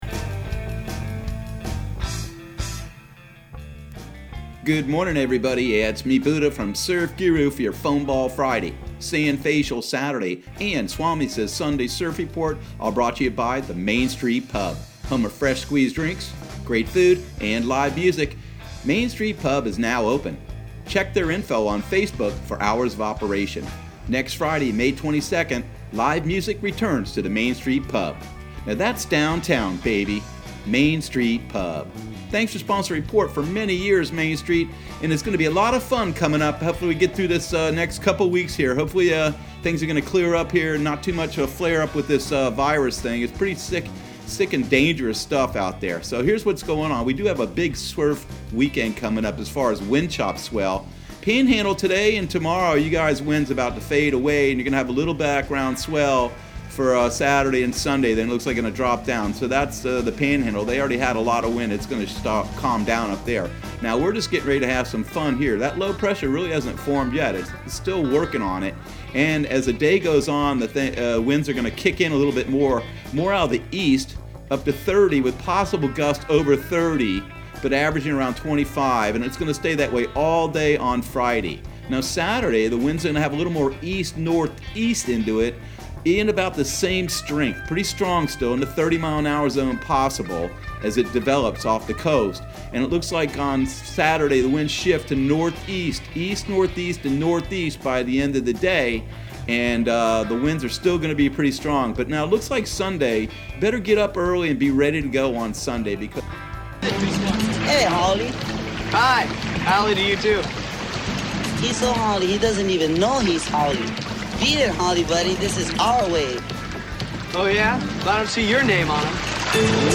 Surf Guru Surf Report and Forecast 05/15/2020 Audio surf report and surf forecast on May 15 for Central Florida and the Southeast.